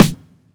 REUA_SNR (2).wav